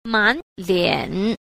7. 滿臉 – mǎn liǎn – mãn kiểm (khắp mặt)